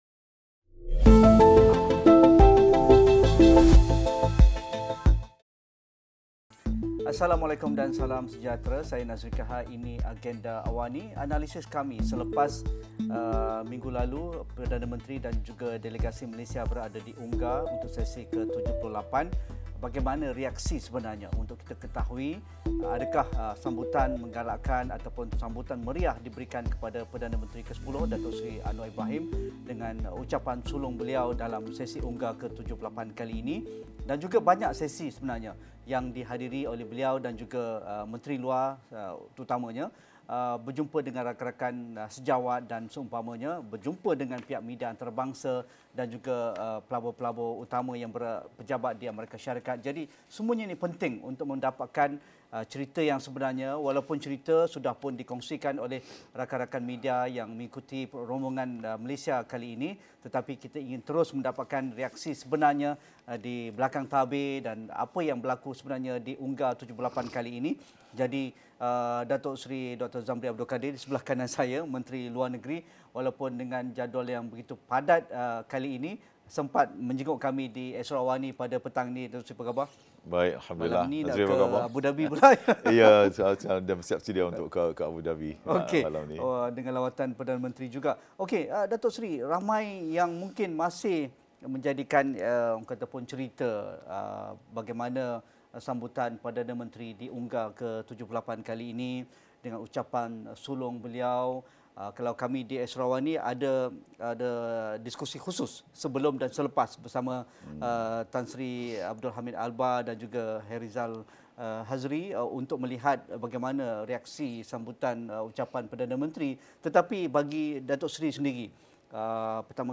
Apa sebenarnya misi dan strategi Malaysia dalam mengukuhkan hubungan antarabangsa dan mempromosi dasar luar negara ketika dunia berdepan dengan pelbagai cabaran? Temu bual khas bersama Menteri Luar Datuk Seri Dr. Zambry Abd Kadir 8.30 malam ini.